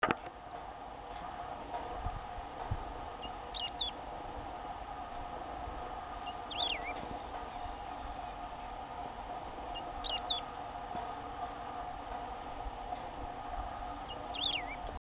Wedge-tailed Grass Finch (Emberizoides herbicola)
Life Stage: Adult
Condition: Wild
Certainty: Photographed, Recorded vocal